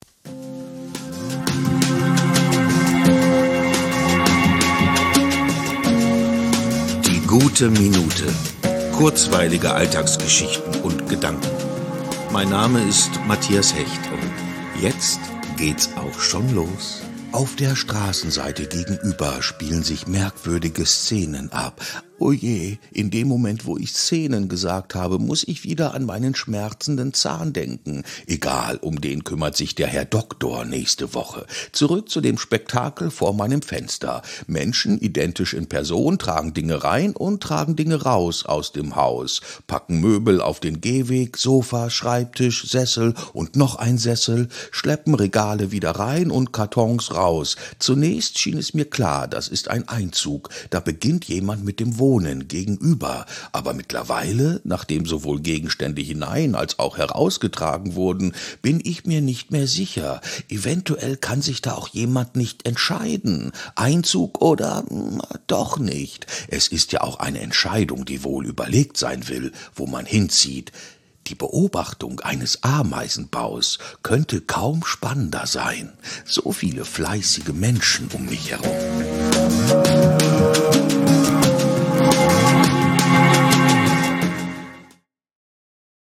mit markanter Schauspieler-Stimme